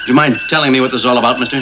Kirk saying "Would you mind telling me what this is all about Mister?" (17.6KB) - wav